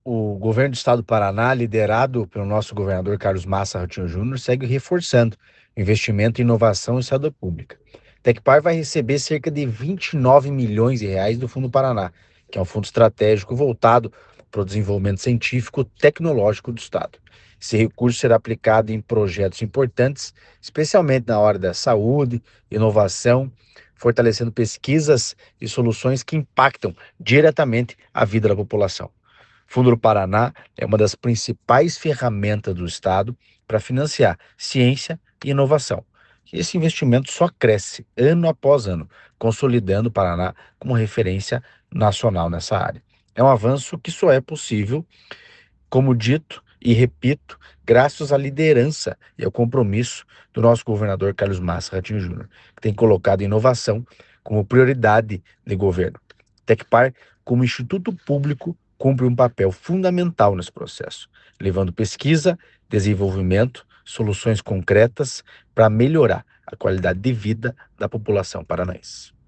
Sonora do diretor-presidente do Tecpar, Eduardo Marafon, sobre os R$ 29 milhões em recursos ao instituto pelo Fundo Paraná